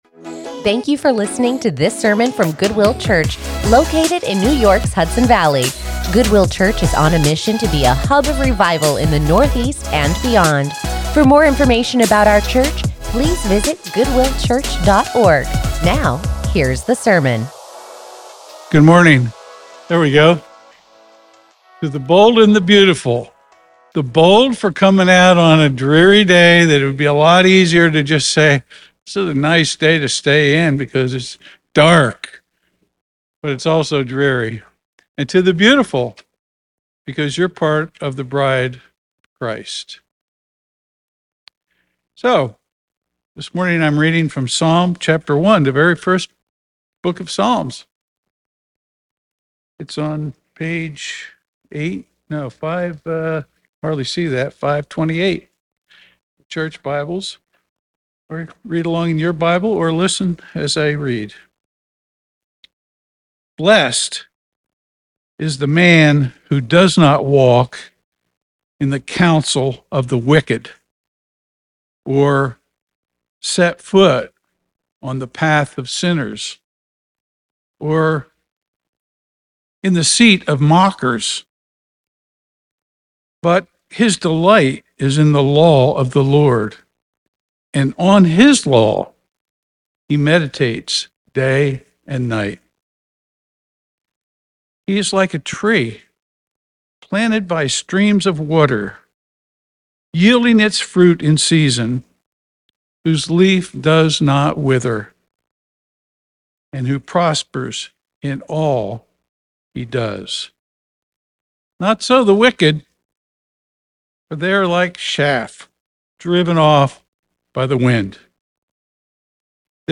Join us in the study of God's Word as we continue our sermon series